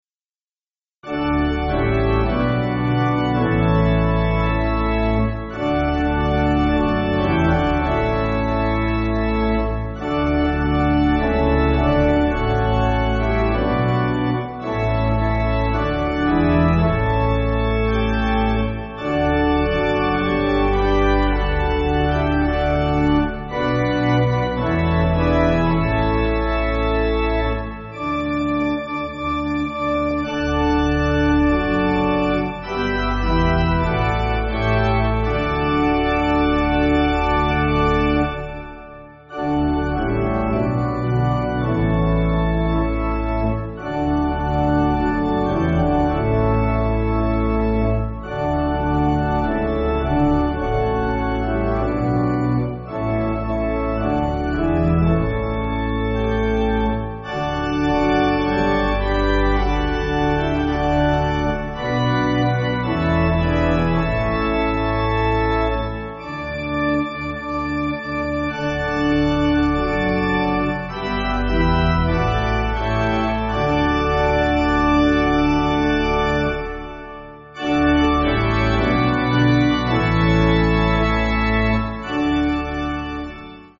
6.5.6.5.D
Organ